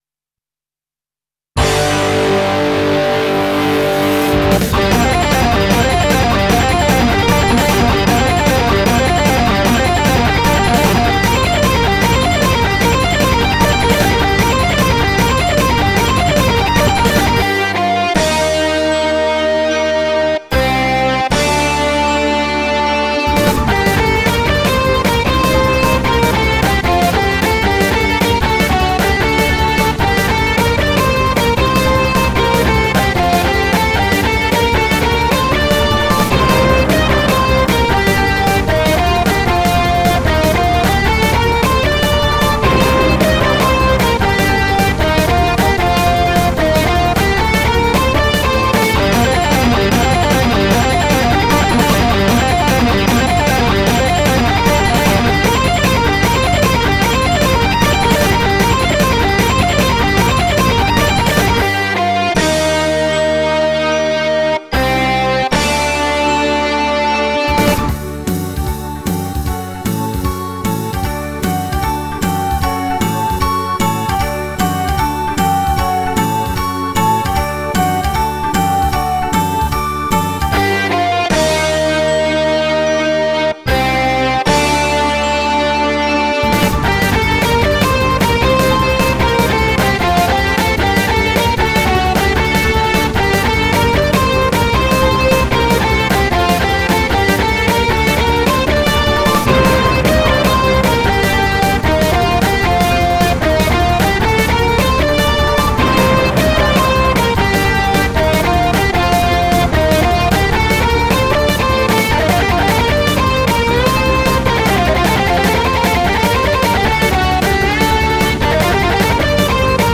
ロック調の曲。SC-88ProのOverdriveを使用。